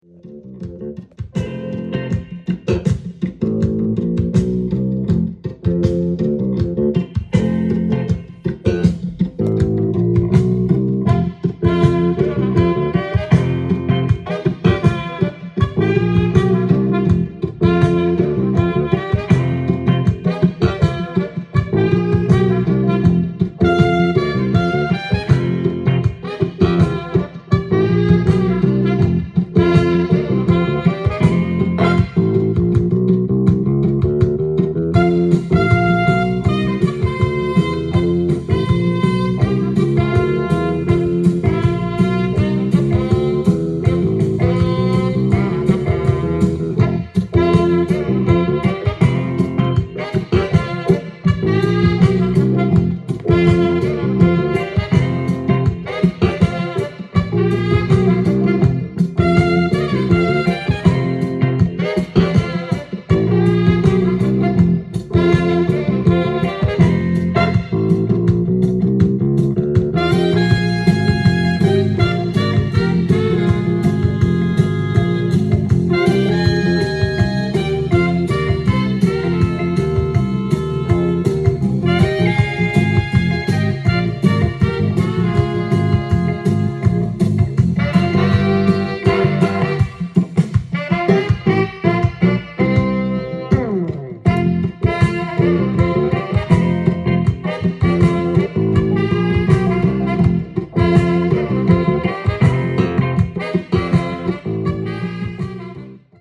ジャンル：FUSION
店頭で録音した音源の為、多少の外部音や音質の悪さはございますが、サンプルとしてご視聴ください。